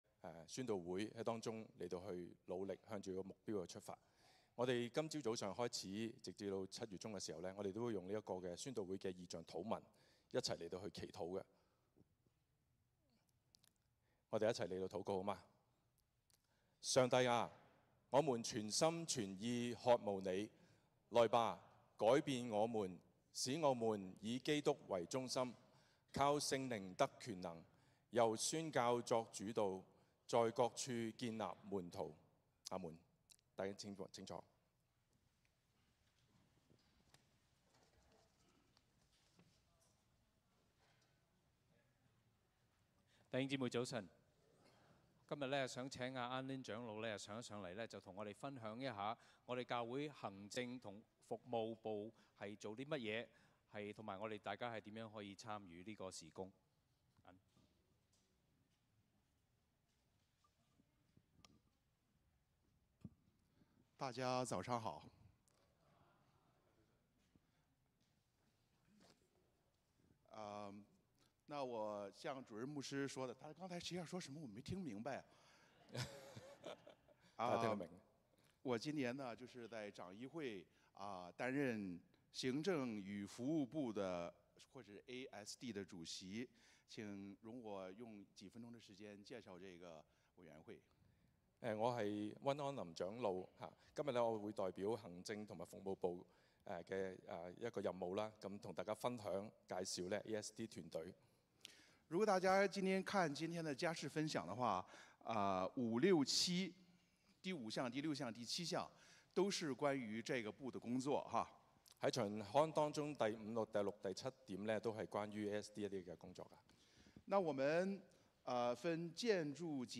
以聖靈為能力的教會 - Cantonese Congregation
帖撒羅尼迦前書 1:1-10 Service Type: 主日崇拜 - 早堂 歡迎大家加入我們的敬拜。